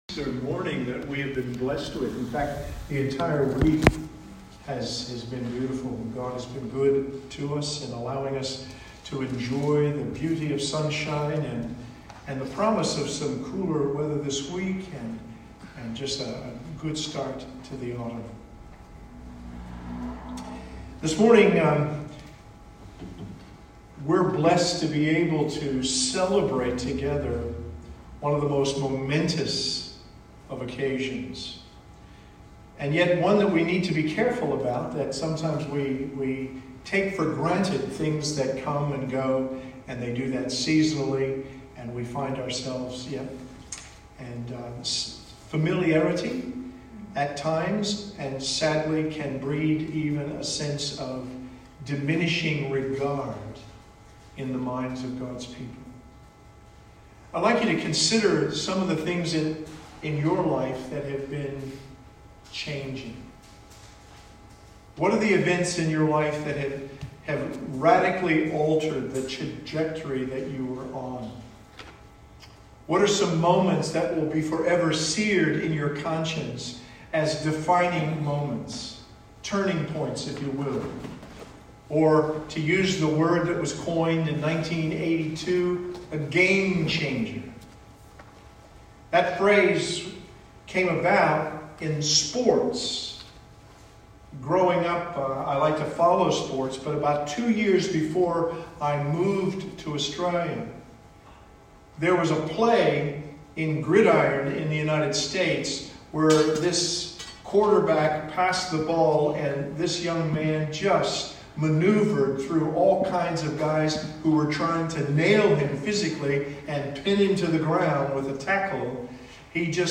Easter Sunday 04.04.2021